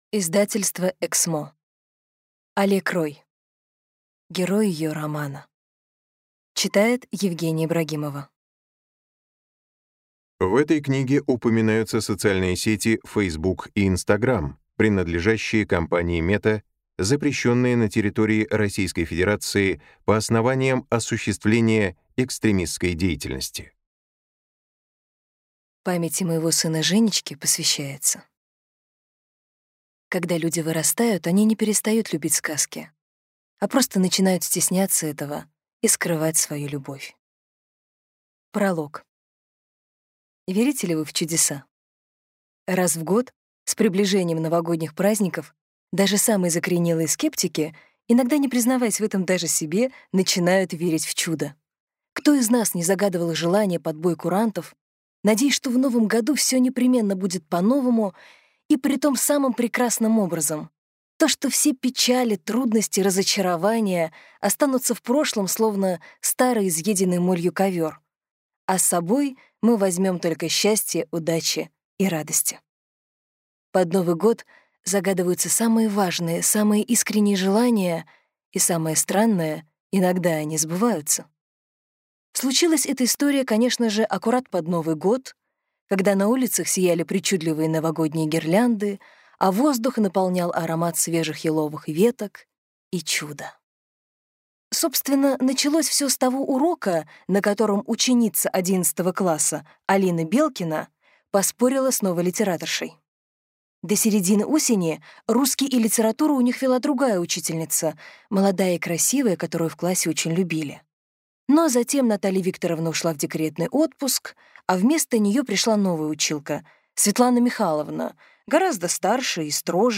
Аудиокнига Герой ее романа | Библиотека аудиокниг